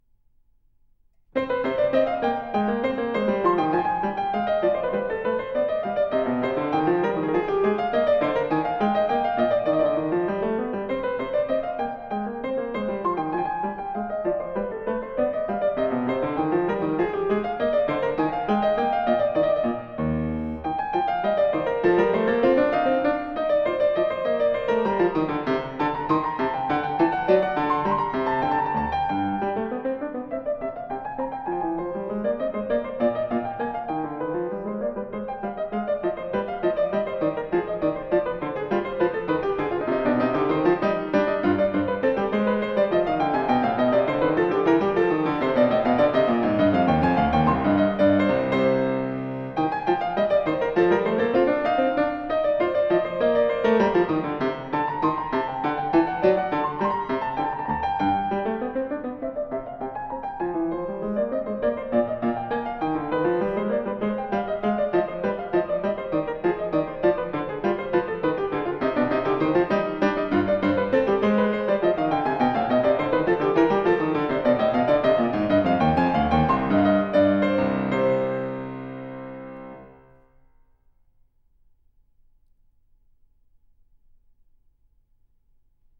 音乐类型：钢琴独奏